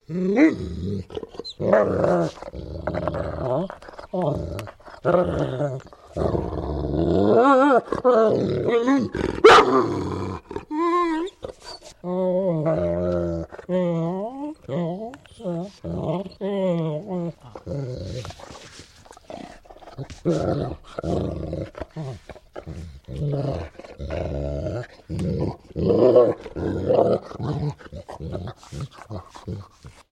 На этой странице собраны звуки собак, играющих с игрушками: лай, повизгивание, рычание и другие забавные моменты.
Звук собаки, грызущей что-то зубами